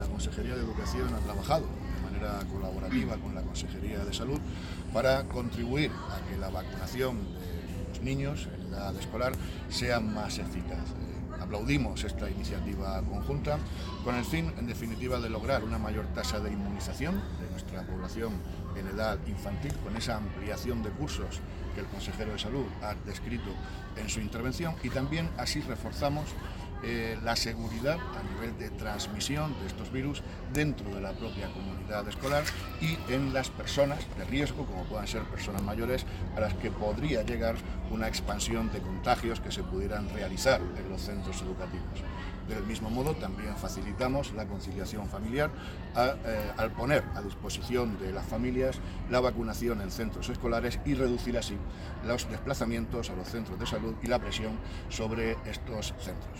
Sonido/ Declaraciones del consejero de Educación y Formación Profesional; Víctor Marín [mp3], sobre la campaña de vacunación en centros escolares.